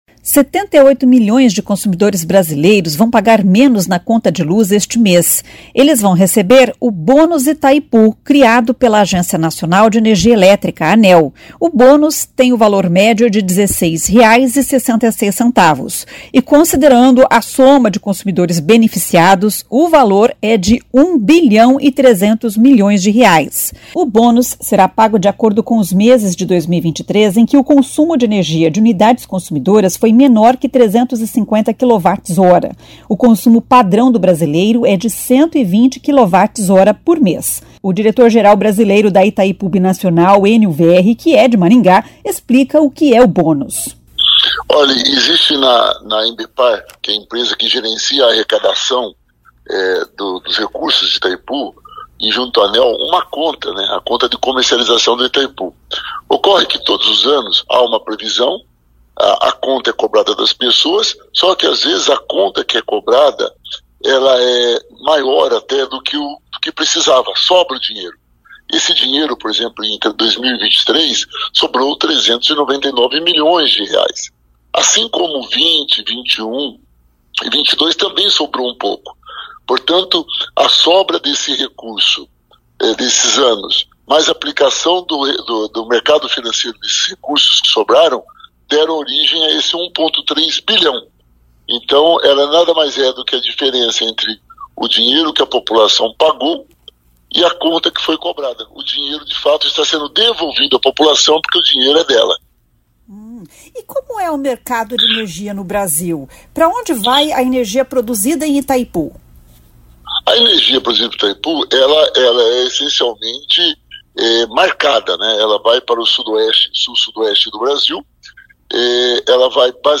O diretor-geral brasileiro da Itaipu Binacional, Ênio Verri, que é de Maringá, explica o que é o bônus.